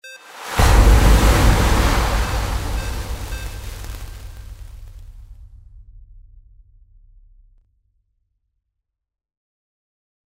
Water Magic Start 01
Stereo sound effect - Wav.16 bit/44.1 KHz and Mp3 128 Kbps
previewSCIFI_MAGIC_WATER_START_WBHD01.mp3